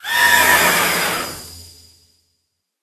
Cri de Spectreval dans Pokémon HOME.